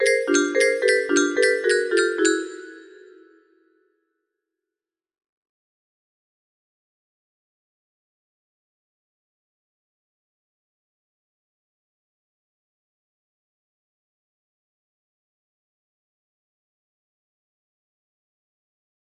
Creepy thing (all) music box melody